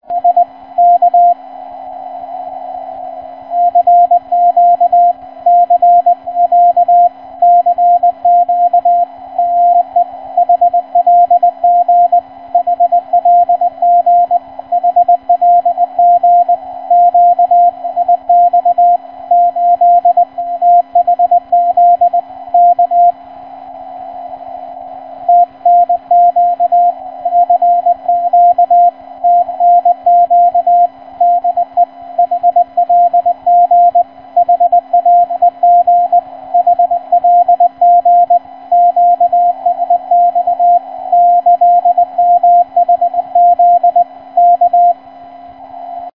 8484 кГц, CW, станция HLG, Сеул, Корея.